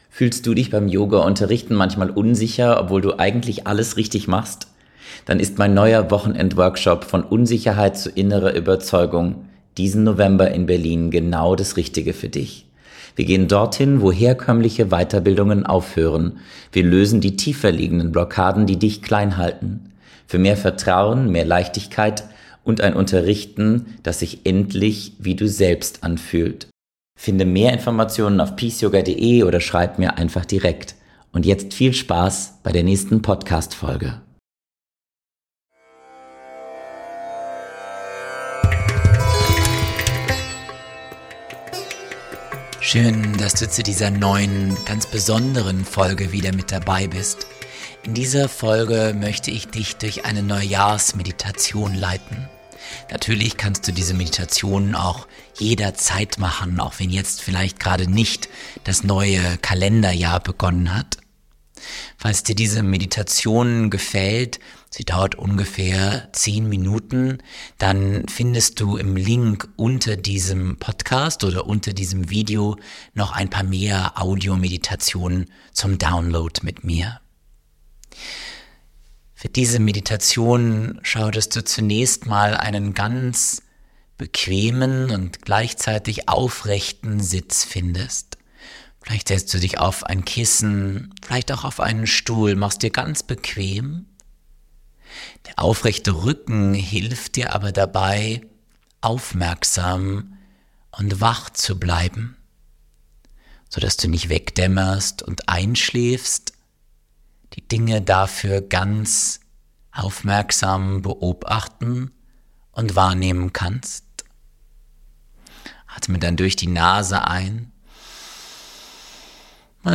Geführte Neujahrsmeditation ~ Ist das noch Yoga?
In dieser besonderen Folge heiße ich dich herzlich willkommen zu einer geführten Neujahrsmeditation. Selbst wenn es nicht direkt zum Jahresbeginn ist, kannst du jederzeit in diese etwa 10-minütige Meditation eintauchen, um innere Ruhe zu finden und neue Vorsätze zu setzen.